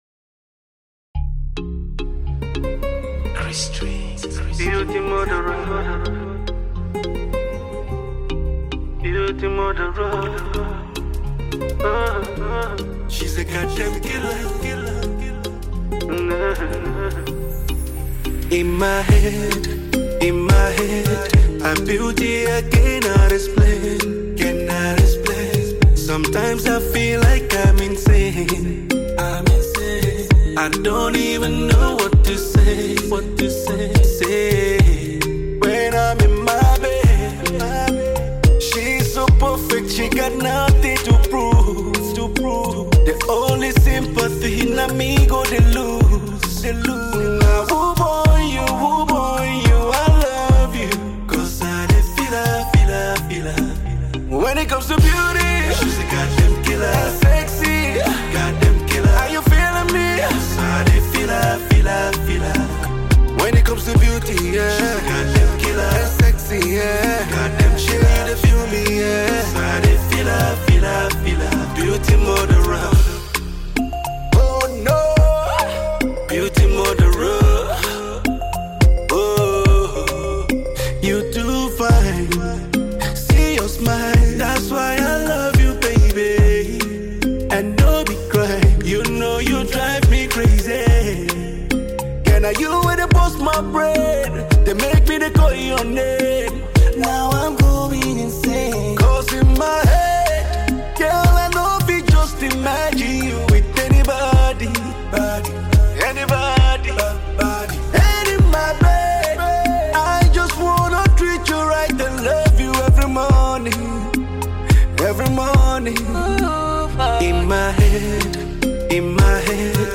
for the brand new Afro R&B melody banger.